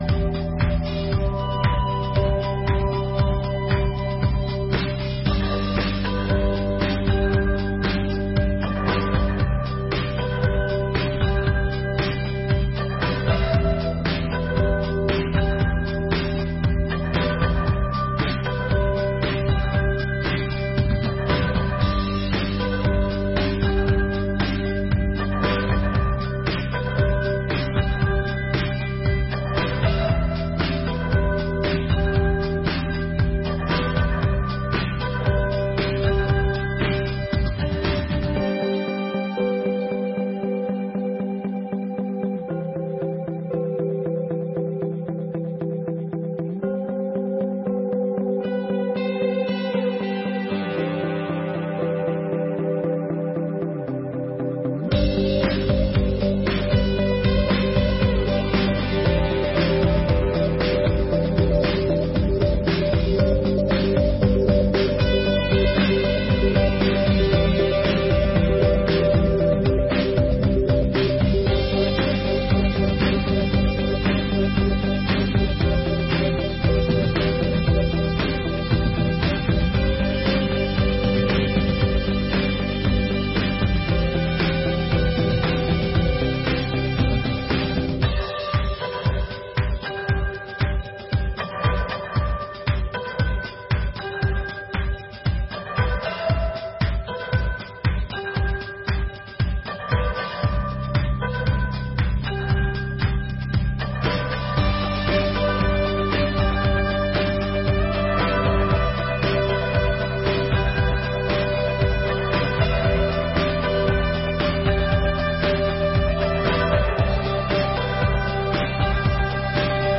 4ª Sessão Extraordinária de 2024